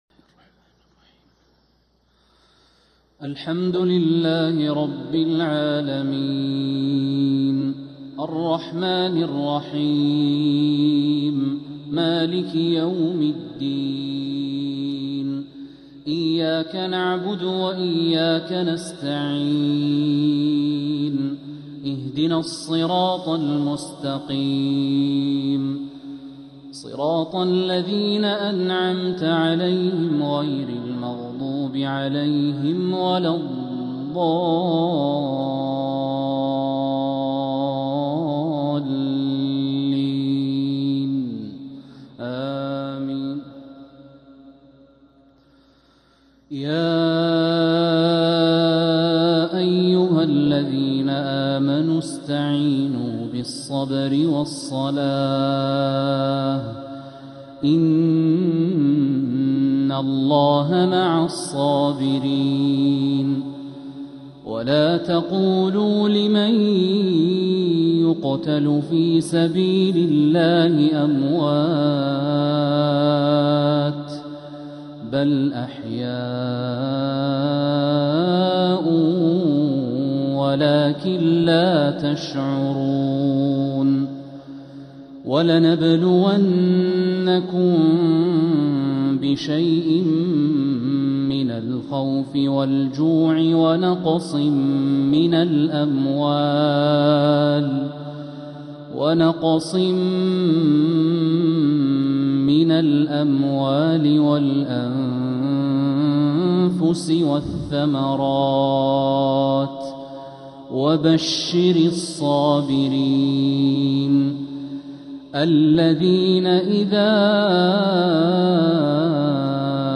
مغرب الجمعة 4 ذو القعدة 1446هـ من سورة البقرة 153-158 | Maghrib prayer from Surah Al-Baqarah 2-5-2025 > 1446 🕋 > الفروض - تلاوات الحرمين